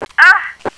death1.wav